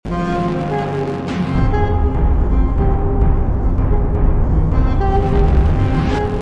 A 10 Thunderbolt Ll In Slowmo Sound Effects Free Download